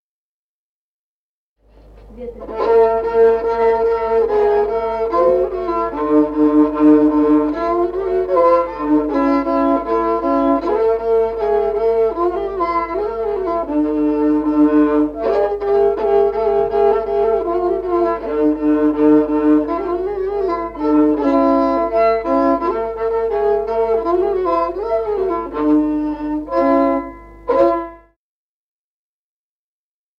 Музыкальный фольклор села Мишковка «Ветреная», партия 2-й скрипки.